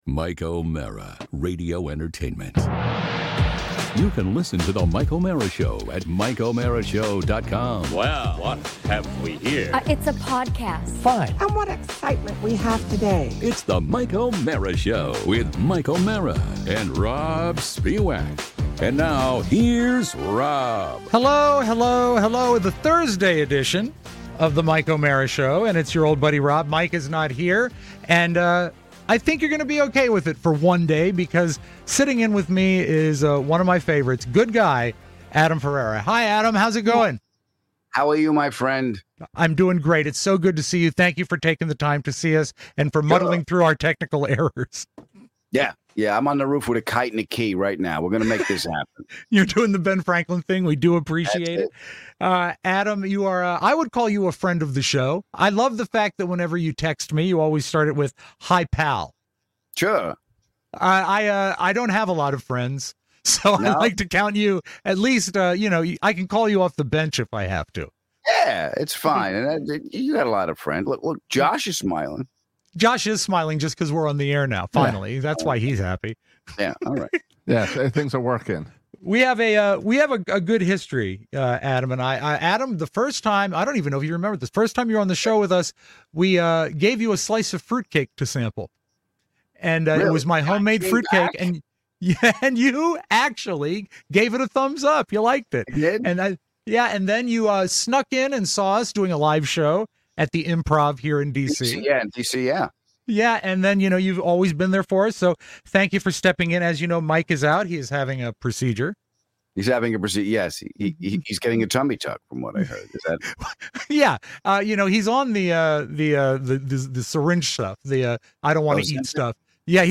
chats with actor/comedian (and show favorite) Adam Ferrara